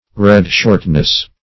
Meaning of red-shortness. red-shortness synonyms, pronunciation, spelling and more from Free Dictionary.
Search Result for " red-shortness" : The Collaborative International Dictionary of English v.0.48: Red-short \Red"-short`\ (-sh?rt`), a. (Metal.)